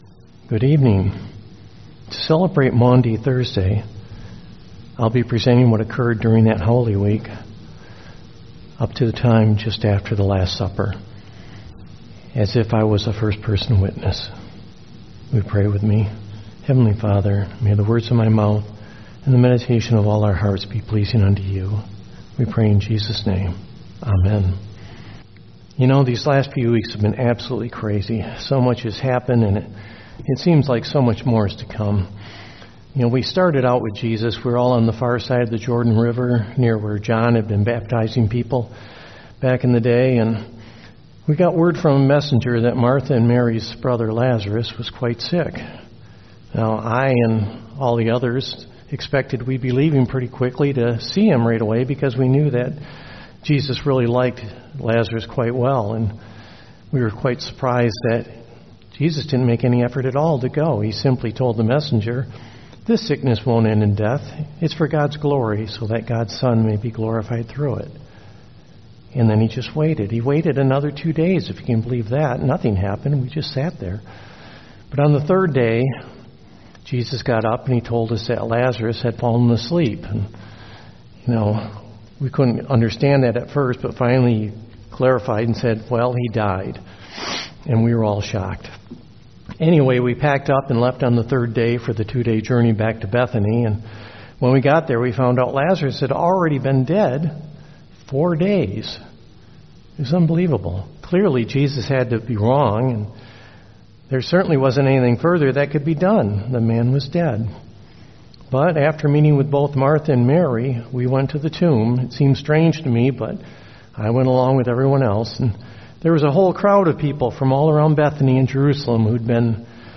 4-9-20 Service – Maundy Thursday